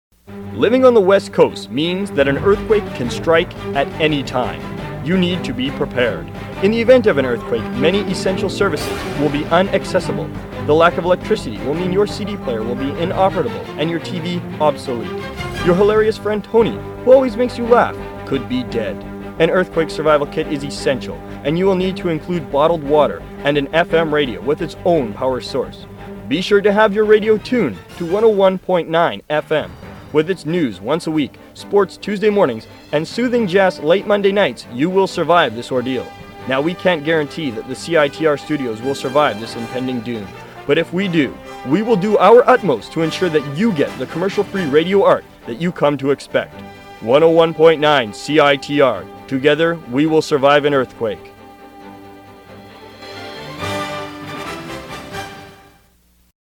Thanks for taking a second to listen to bad old college radio from the late 90s and 2000s.